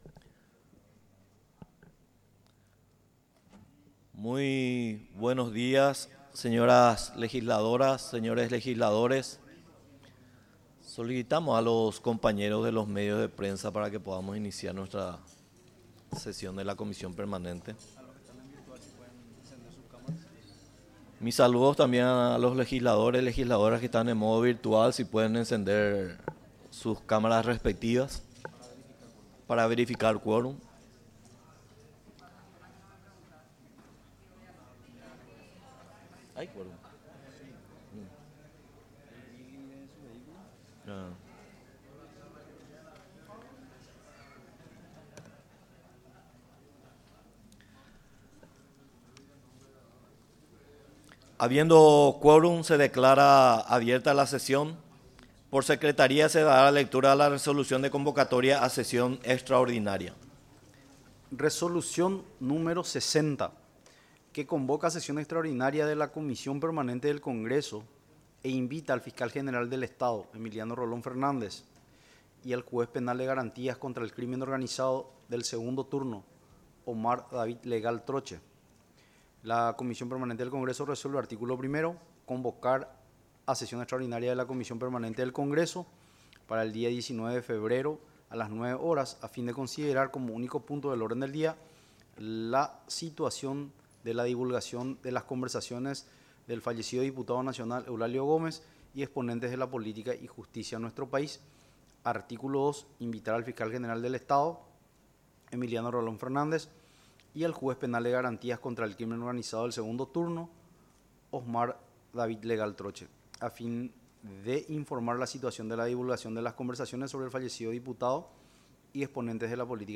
Inicio de la sesi�n y consideraci�n del acta de la sesi�n anterior
Comisi�n Permanente del Congreso Sesi�n Extraordinaria, 19 de febrero de 2025